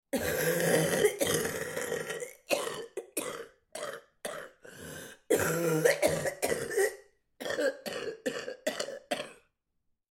Характерный звук кашля при бронхите у женщины